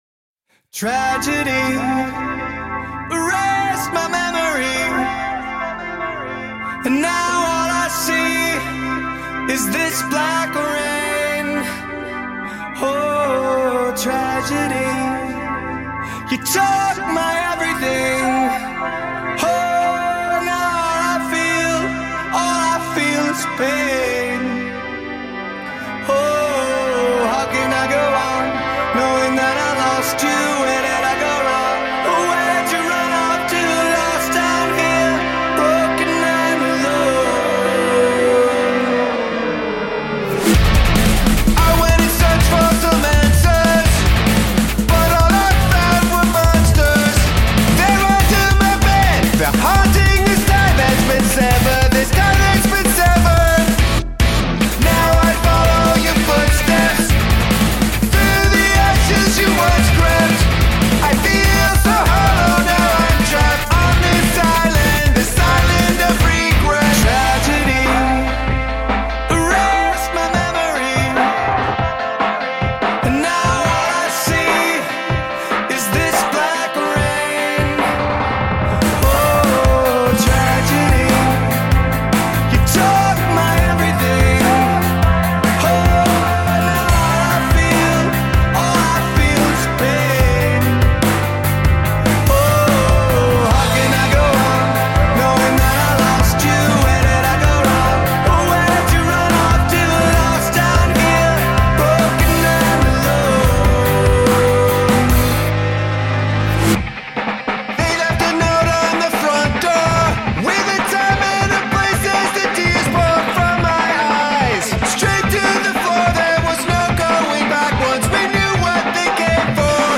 American rock band